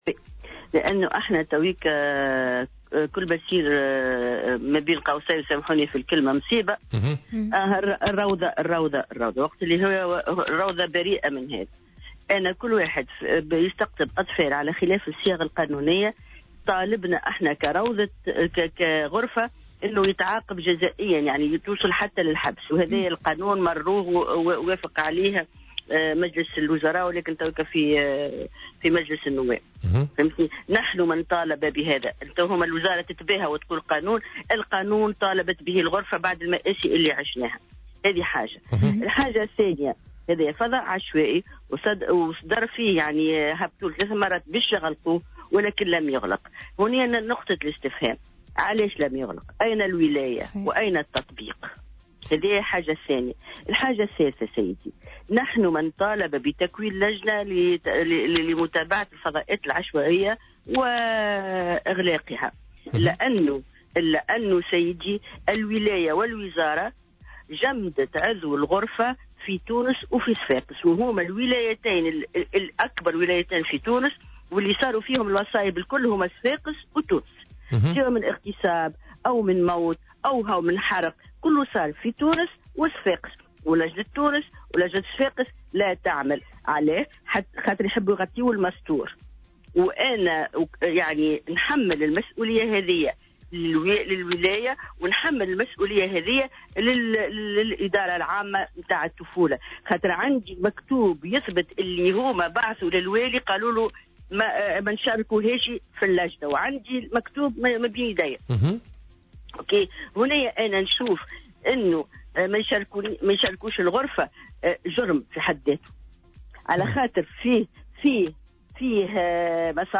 وأضافت في تصريح ل"الجوهرة أف أم" أن الغرفة طالبت بغلق كل الفضاءات العشوائية لكن دون أي نتيجة، مشيرة إلى أن الوزارة جمّدت عضو الغرفة في تونس وصفاقس على الرغم من أنهما الولايتان الأكثر تسجيلا للاخلالات، حسب قولها.